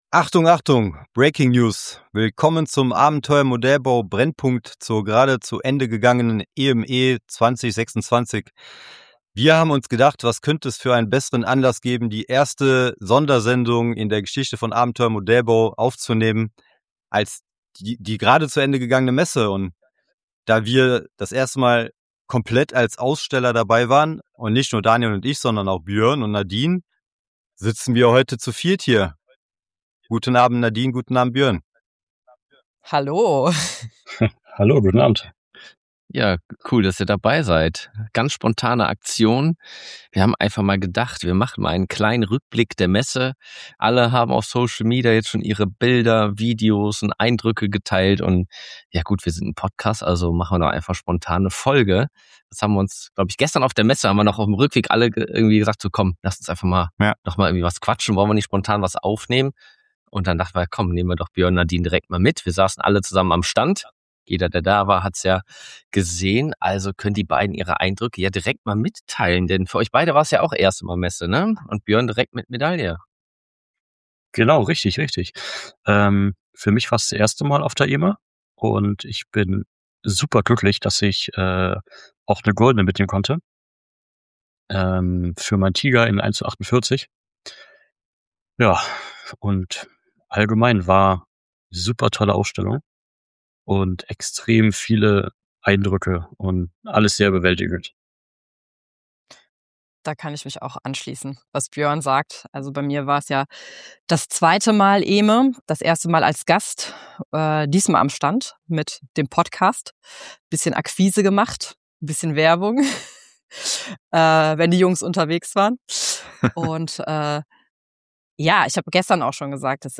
Und darum sitzen wir einen Tag nach der Ausstellung vor den Mikrophonen und lassen das vergangene Wochenende Revue passieren.